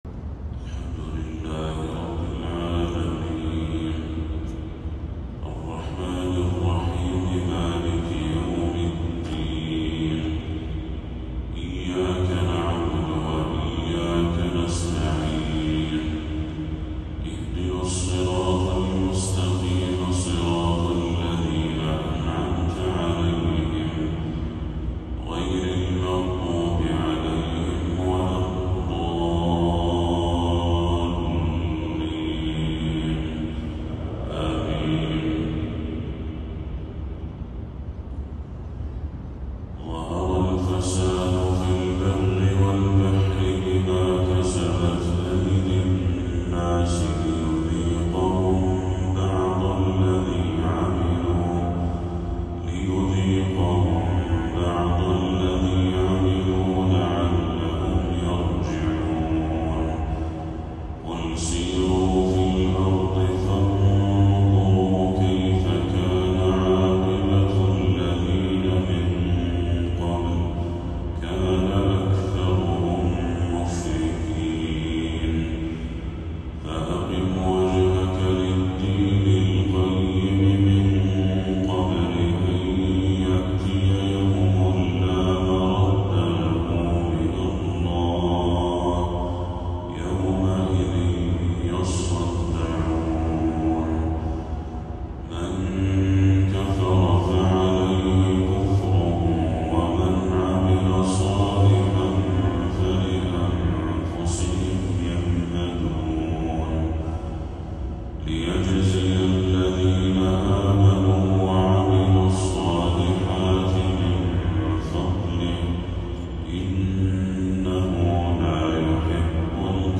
تلاوة خاشعة لخواتيم سورة الروم